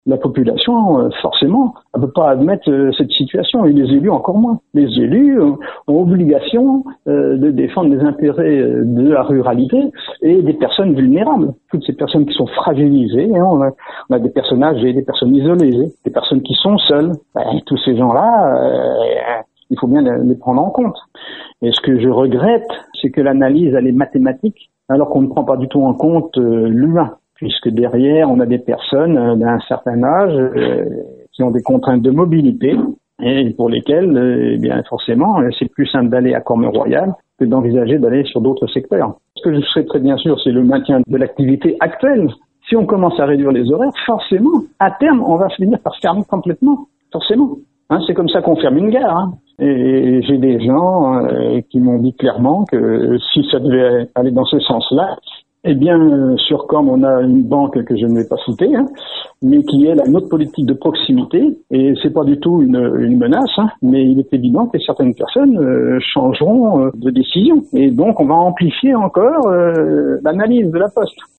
On écoute le maire Alain Margat :